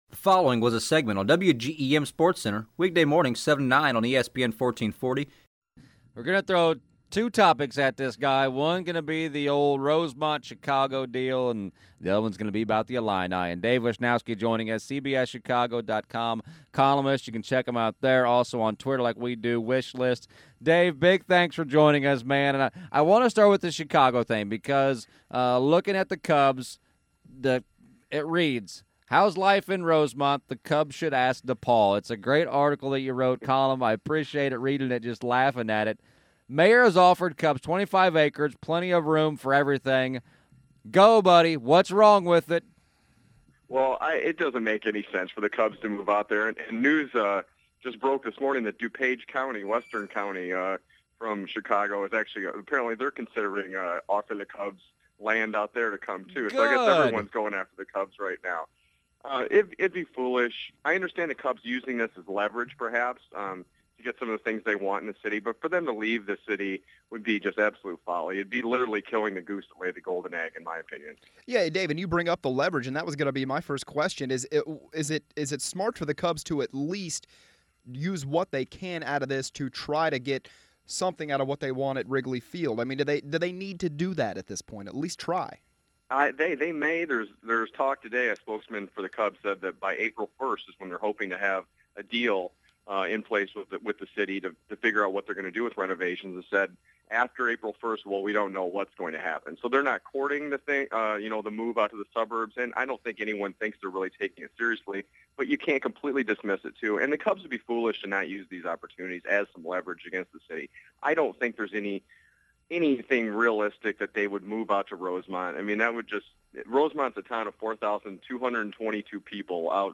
Wisch List on the air … in Quincy